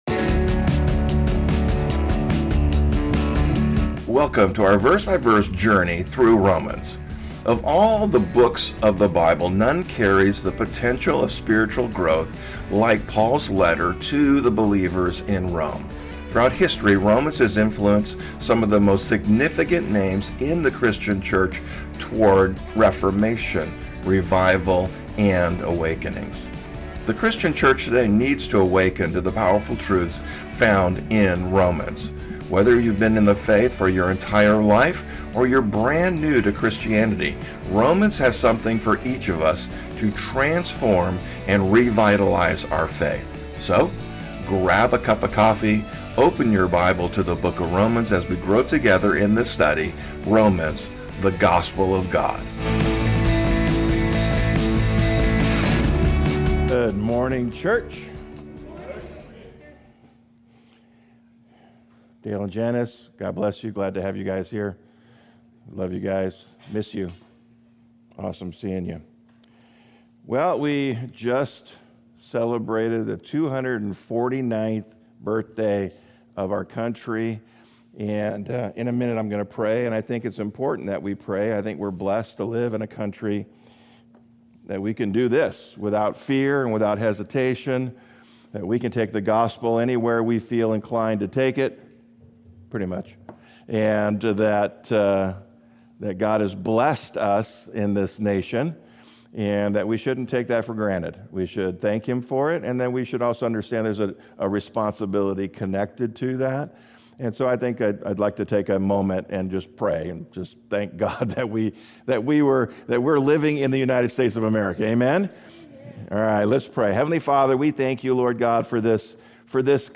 The Sermons - Calvary Chapel French Valley